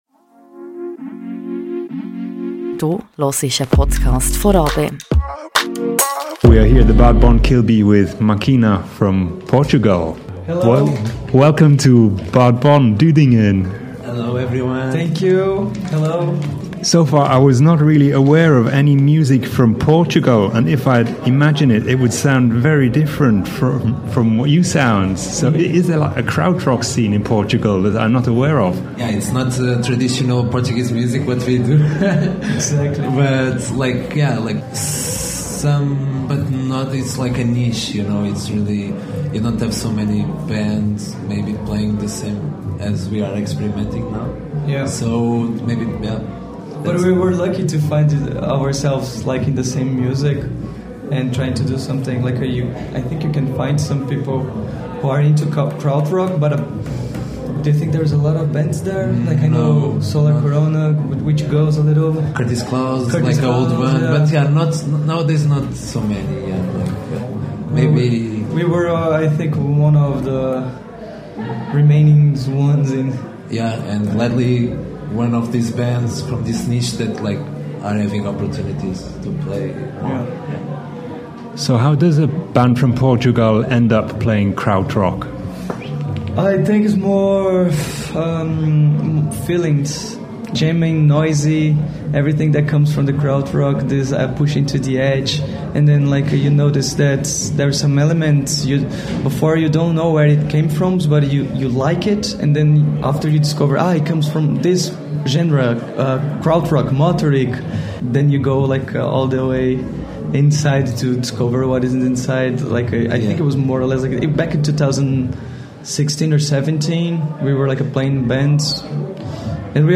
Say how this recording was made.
All this and more in this conversation with the band backstage at the Bad Bonn Kilbi 2025.